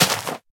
minecraft / sounds / dig / grass2.ogg
grass2.ogg